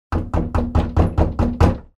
Стучат кулаком
Характер стука нейтральный, хотя и кулаком. Дверь из дерева, но небольшая, и не плотно стоящая в косяке.